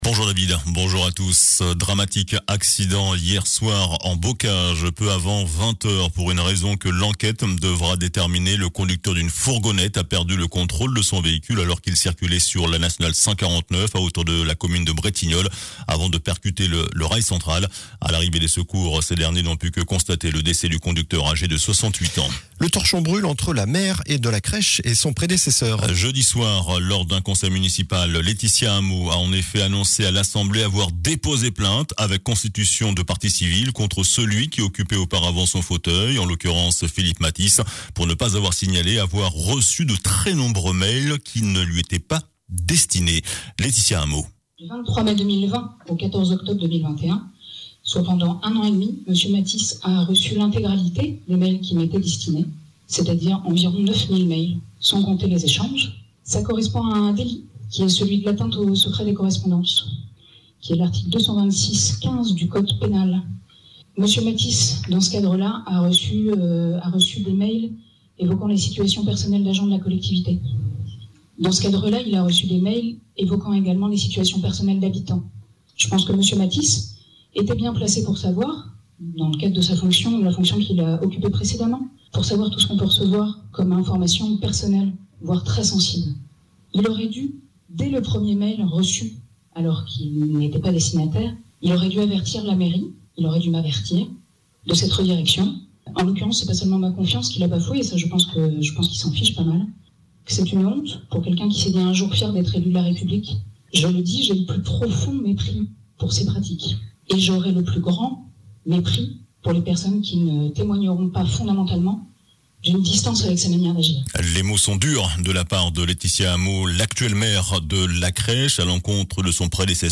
JOURNAL DU SAMEDI 12 MARS